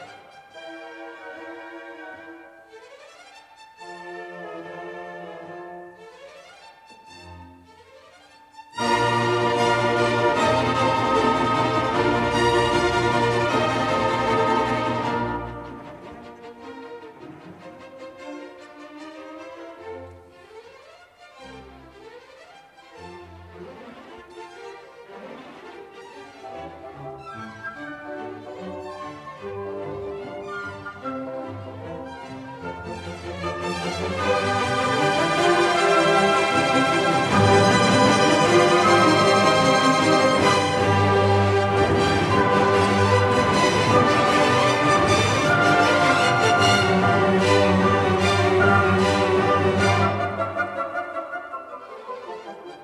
This part starts with quiet, rising and falling semitones. Then, suddenly a powerful burst of the instruments reminds us that this is still a musical comedy.